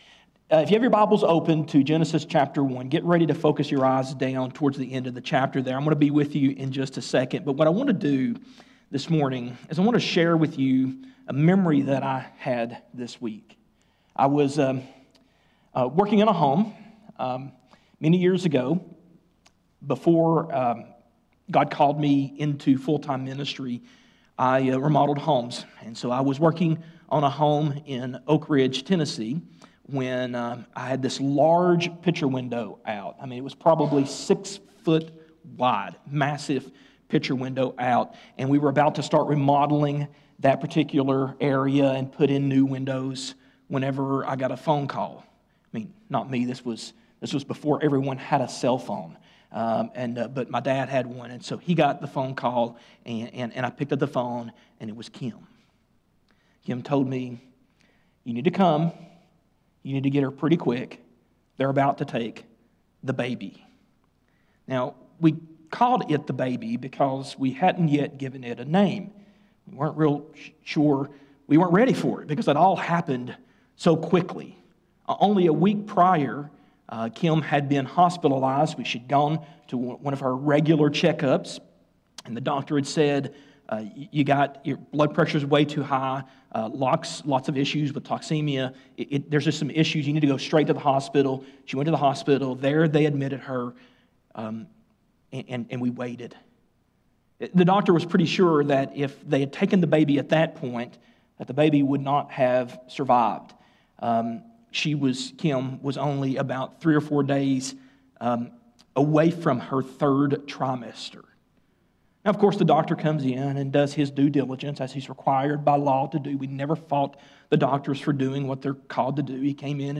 Today's sermon explored the sanctity of human life and how we can engage in compassionate, gospel-centered conversations about abortion. We discussed three key questions: Who determines when life begins? How important is life? Do rights have limits?